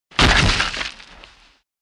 bodyfalldirt04.mp3